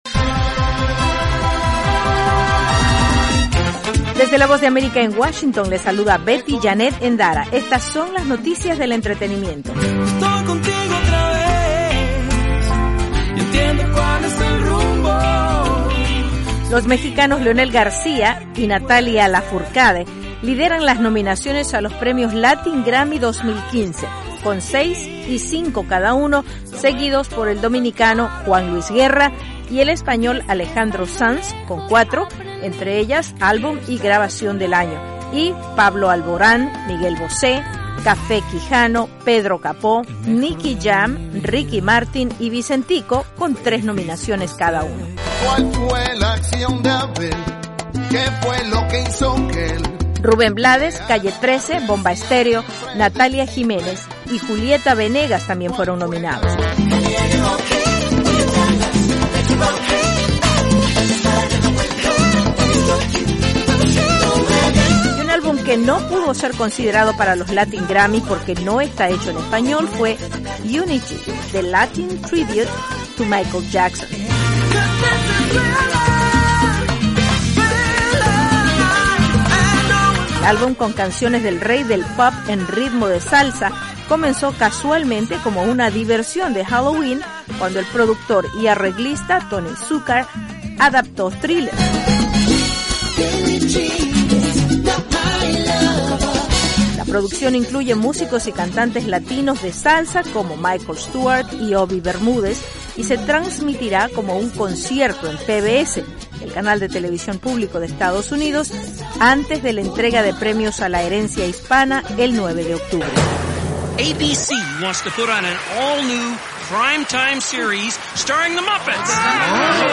VOA: Noticias del Entretenimiento